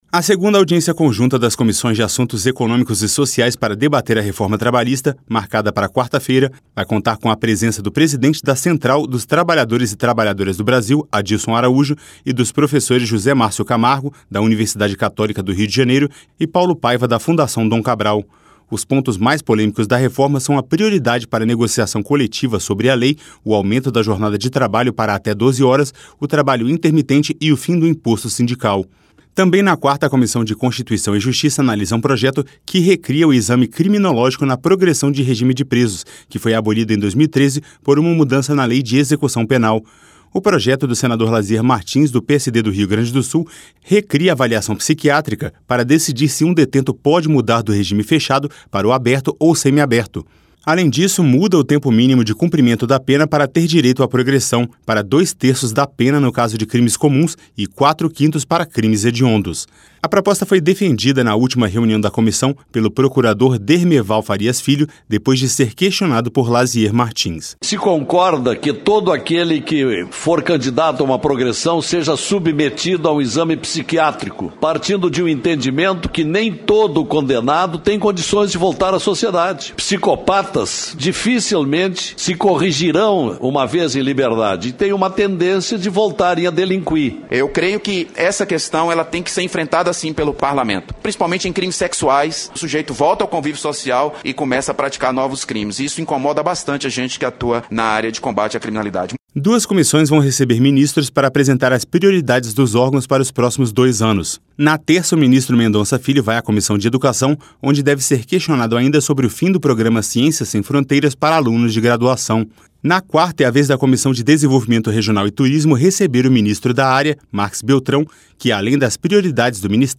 Ouça os detalhes da agenda das comissões para esta semana no áudio do repórter